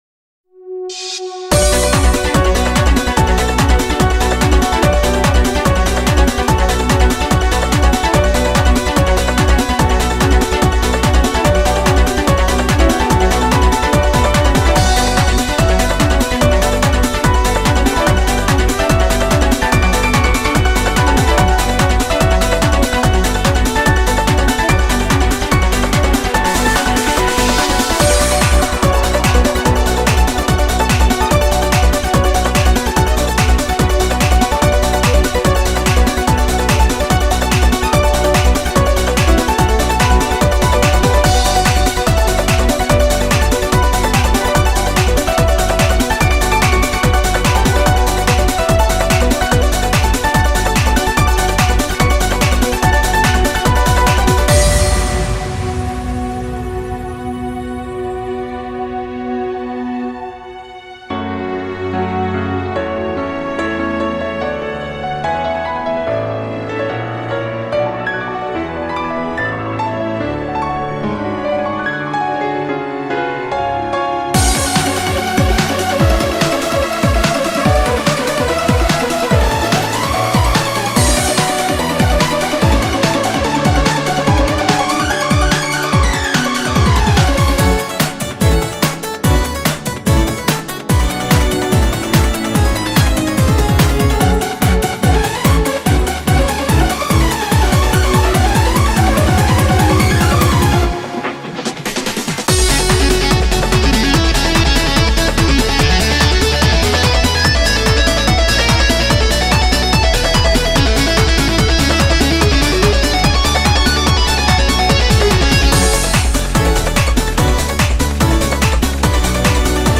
BPM73-145
Audio QualityPerfect (High Quality)
Comments[TRANCE]